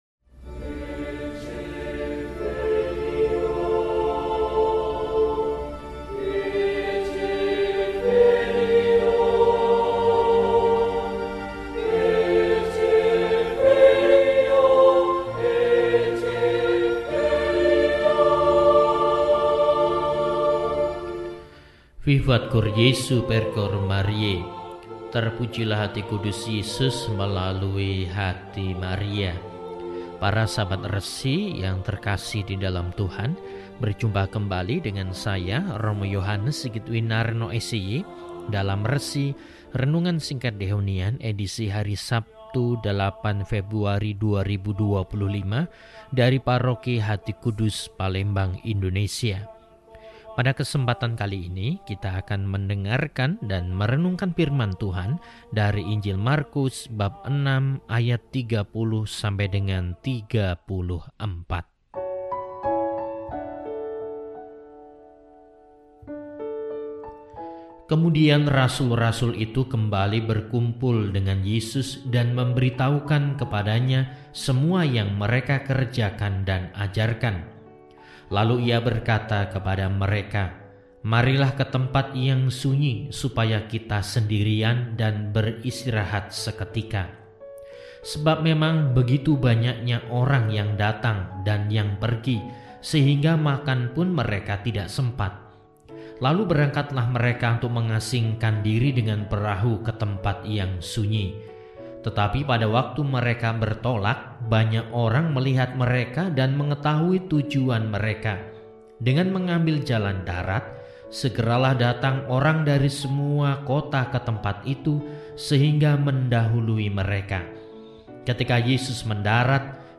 Sabtu, 08 Februari 2025 – Hari Biasa Pekan IV – RESI (Renungan Singkat) DEHONIAN